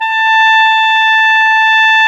WND  CLAR 0L.wav